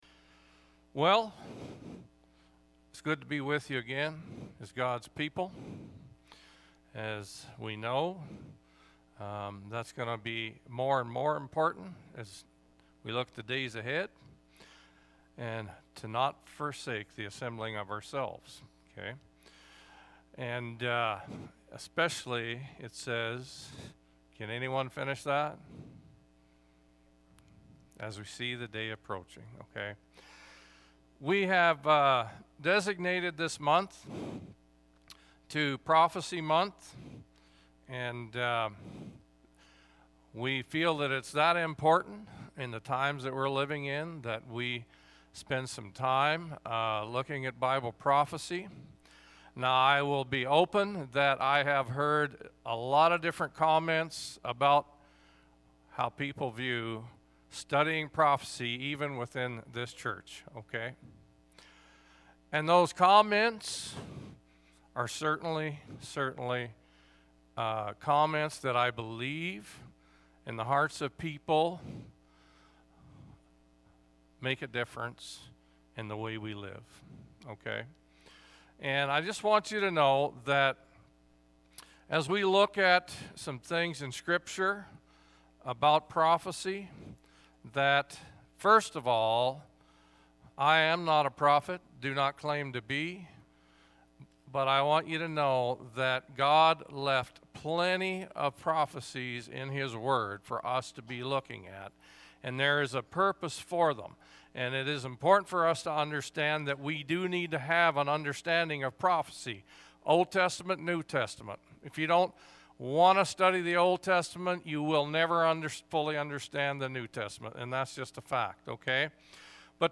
Oct-3-2021-sermon-audio.mp3